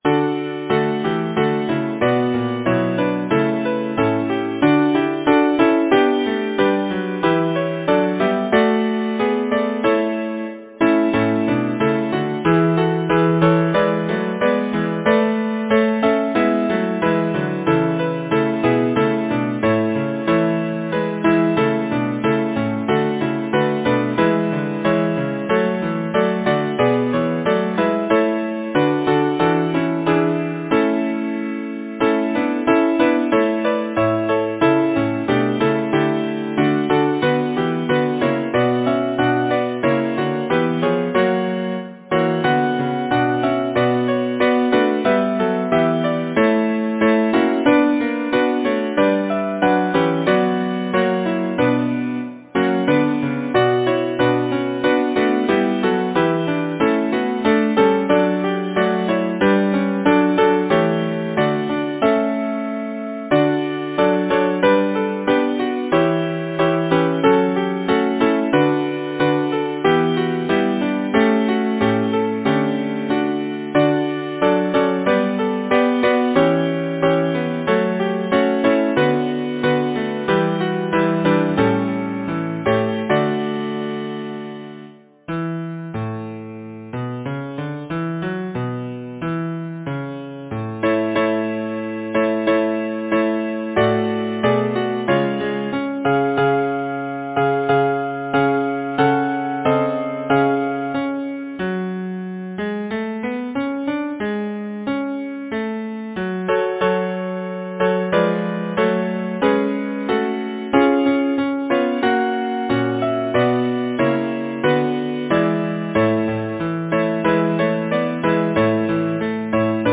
Title: Bonie Bell Composer: Arthur Berridge Lyricist: Robert Burns Number of voices: 4vv Voicing: SATB Genre: Secular, Partsong
Language: English Instruments: A cappella